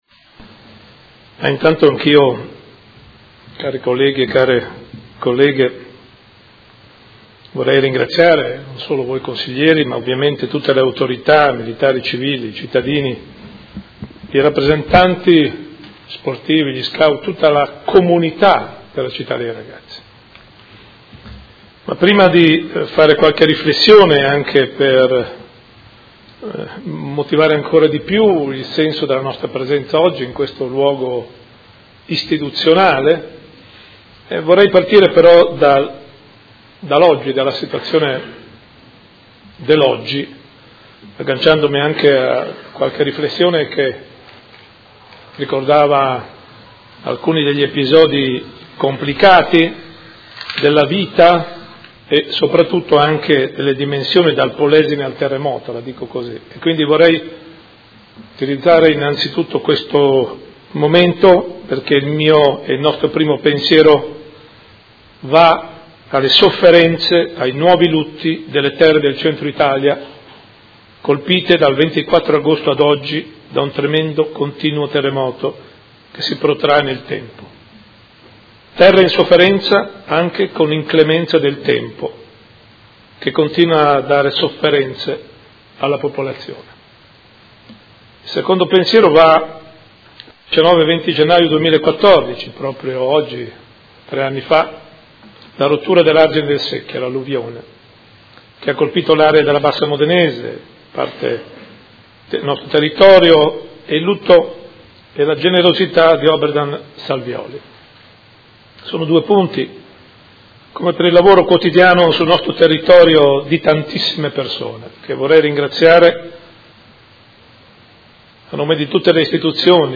Seduta del 19/01/2017 CITTA’ DEI RAGAZZI A 70 ANNI DALLA SUA NASCITA il Consiglio Comunale di Modena la ricorda insieme al suo fondatore Mons. MARIO ROCCHI